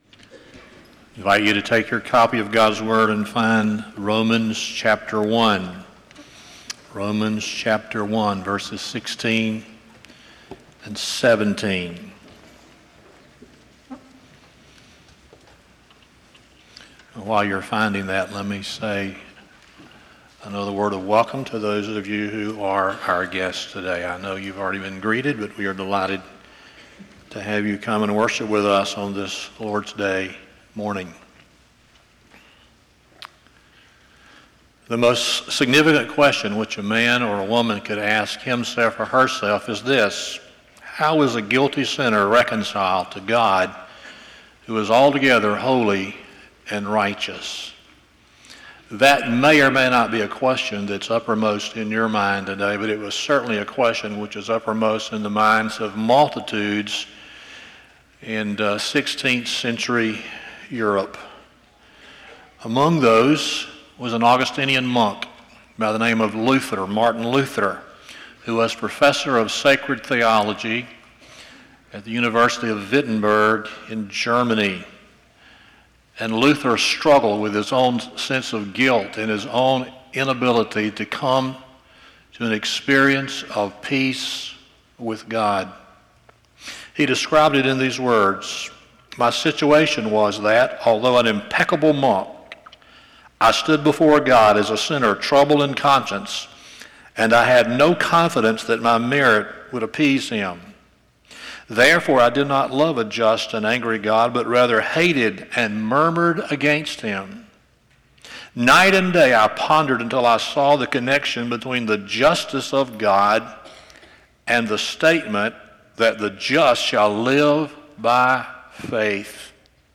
Romans 1:16-17 Service Type: Sunday Morning “Justification”